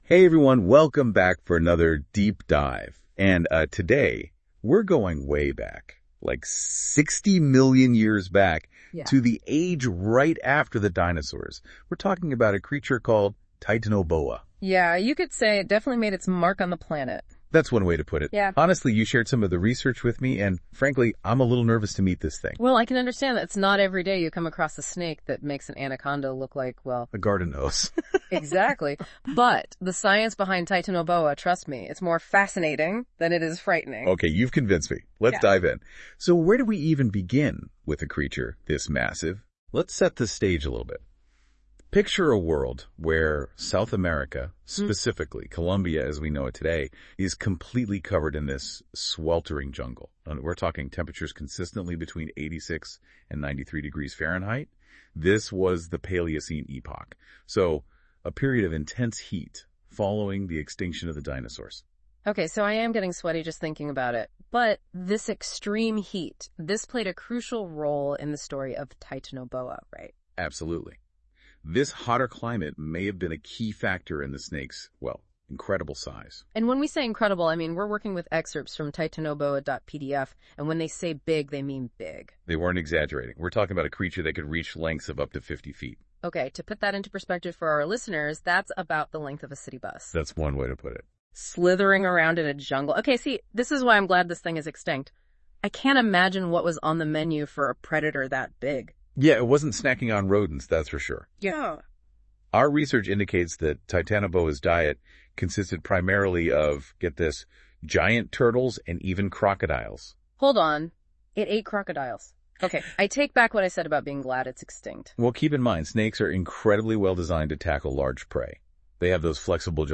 Discussion / Podcast on Titanoboa cerrejonensis